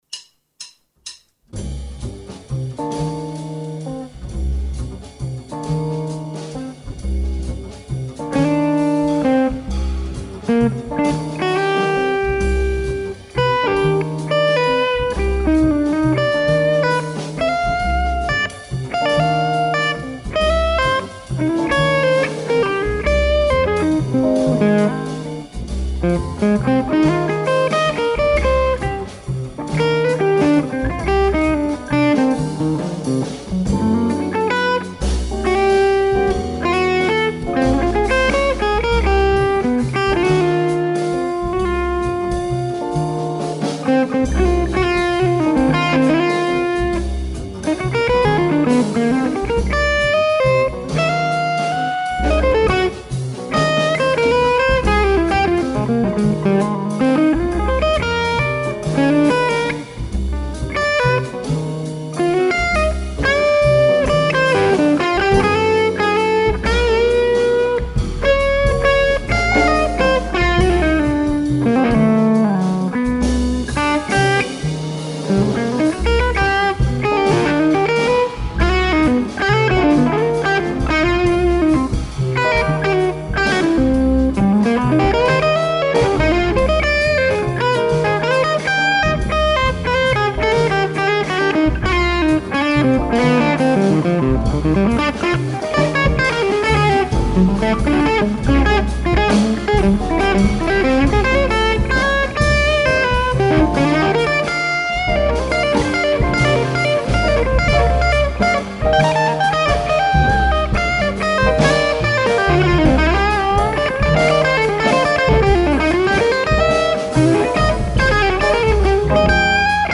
This is Baker RF on bridge > Bludo Bluesmaster > Bludolator > TC2290 > G1265 > SM57 > cheap EMU interface
Volume was a little low still and the micing was so basic (ie no multimics, ribbons, condensers yada yada) but you guys get the idea. THe amp has a silky edge to it and really sings!
The delay really fattens up the tone.